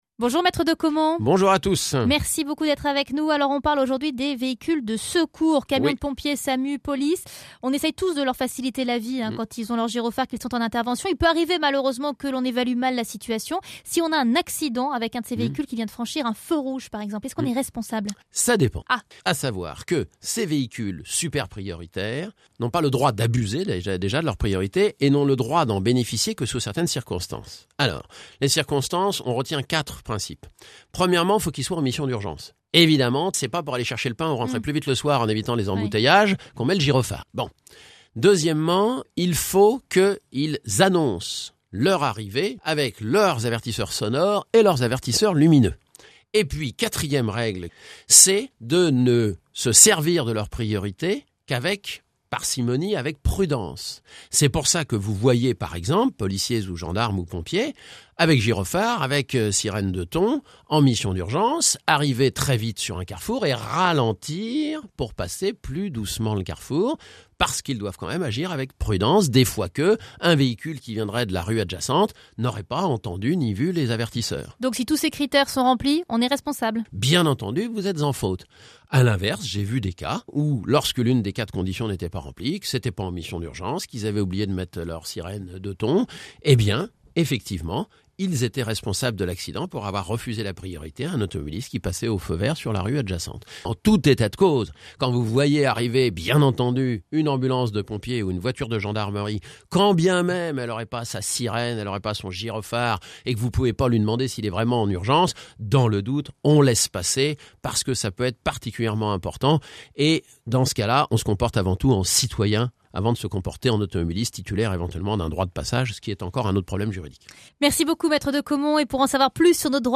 Chronique du 23/12/2012 – Accident avec un véhicule de secours